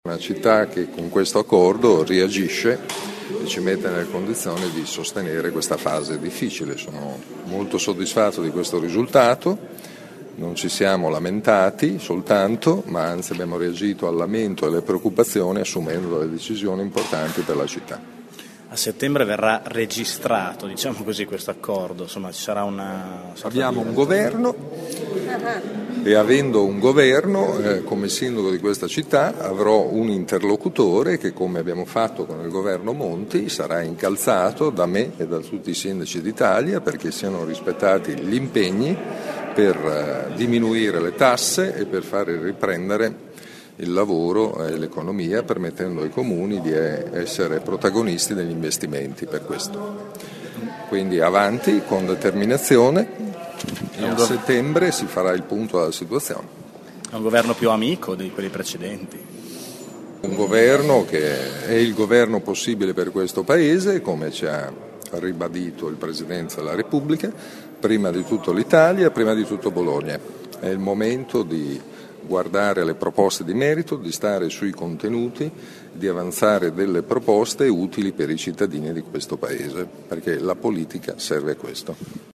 Il sindaco Virginio Merola: “La città sa reagire alla crisi”
merola21.mp3